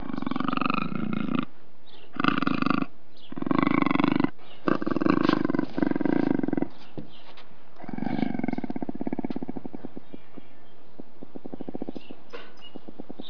دانلود صدای حیوانات جنگلی 91 از ساعد نیوز با لینک مستقیم و کیفیت بالا
جلوه های صوتی